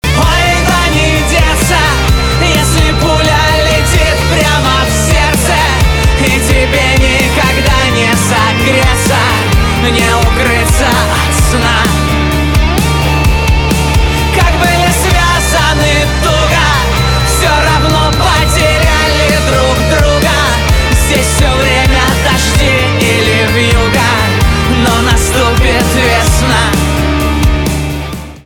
русский рок
грустные
барабаны , гитара